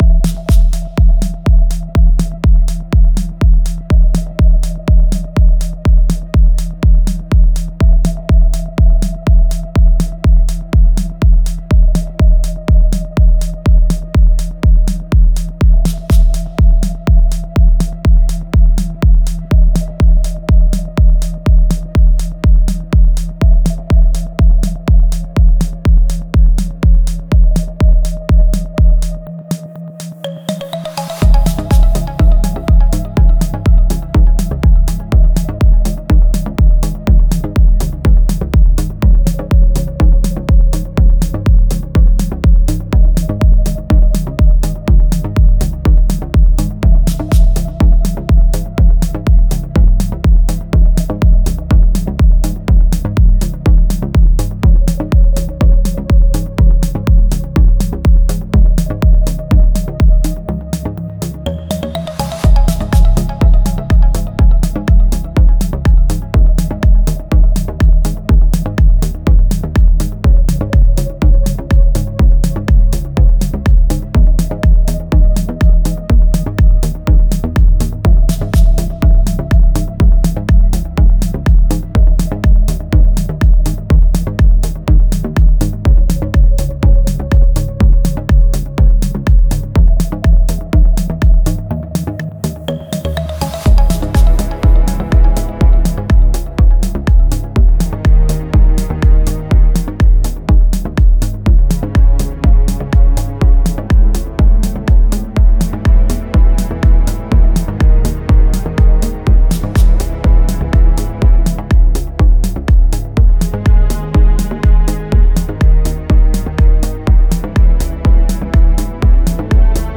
Genre: Melodic Techno